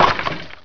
WOODHIT.WAV